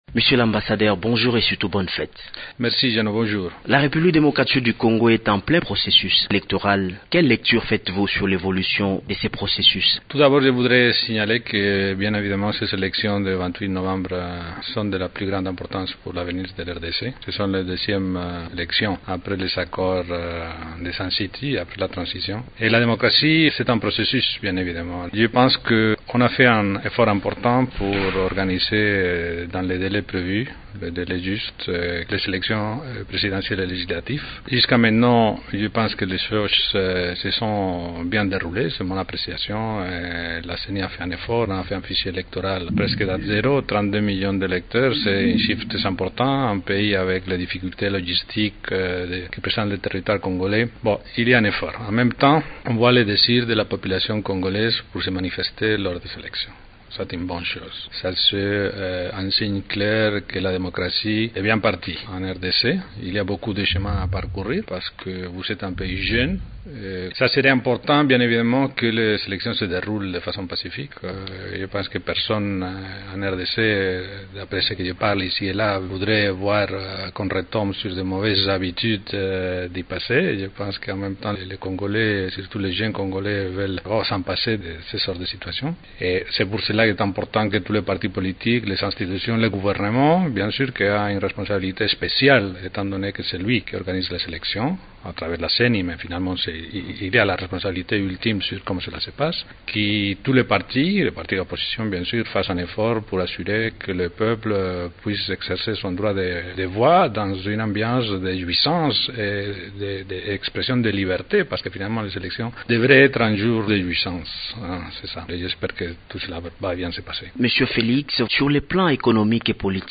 L’ambassadeur du Royaume d’Espagne en RDC, Félix Costales Artieda invite les Congolais à s’impliquer pour la réussite des élections de 2011. il s’exprimait, mercredi 12 octobre à Kinshasa, à l’occasion de la fête nationale espagnole.